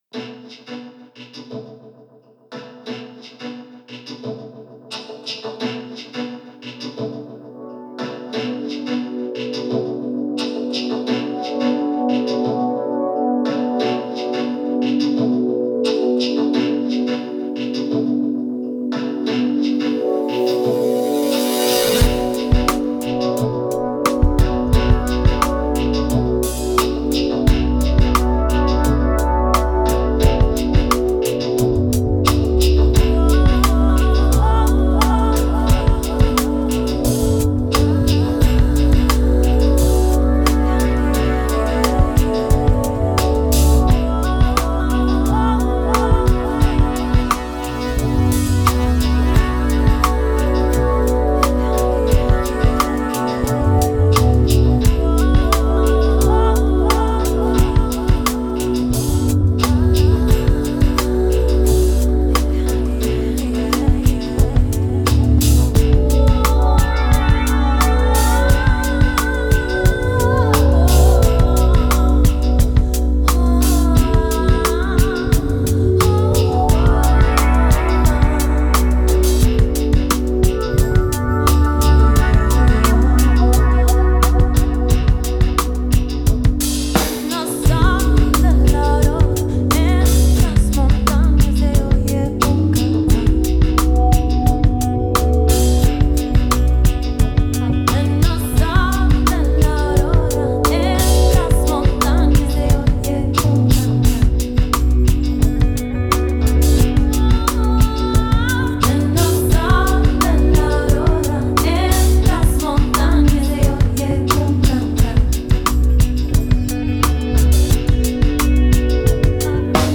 Genre: Downtempo, Chillout, World.